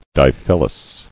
[di·phyl·lous]